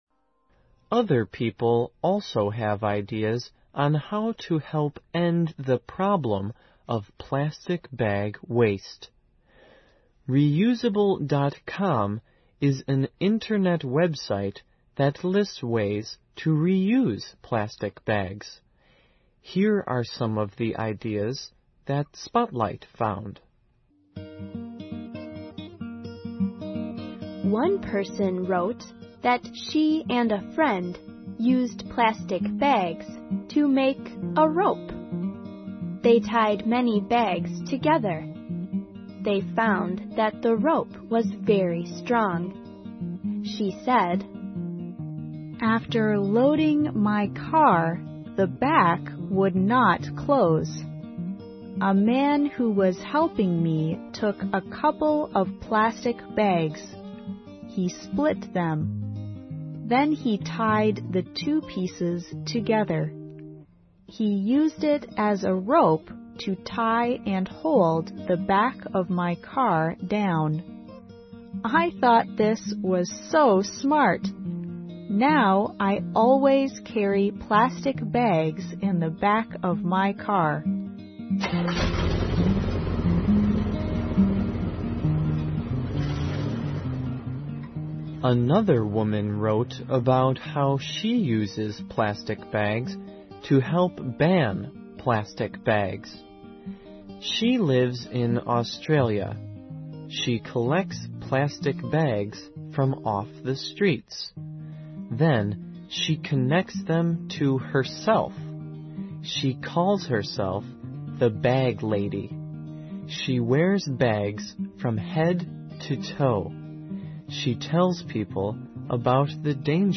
环球慢速英语 第105期:塑料袋废物(6)